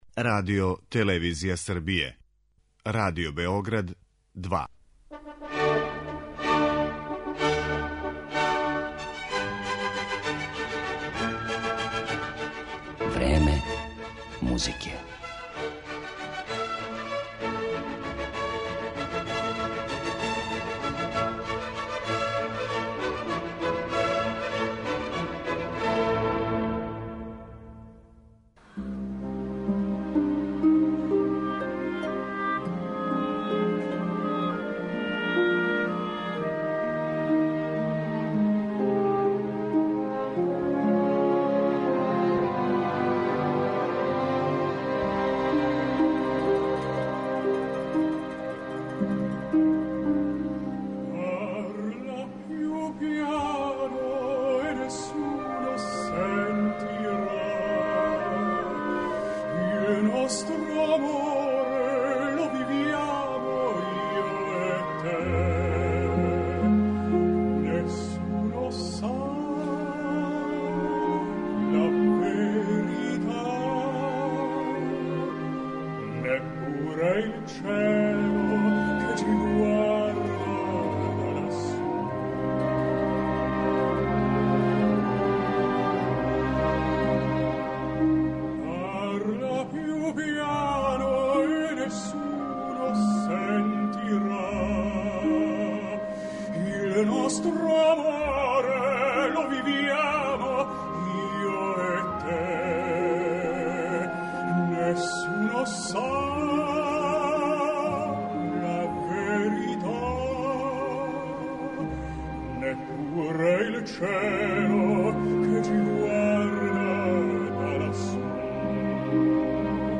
тенор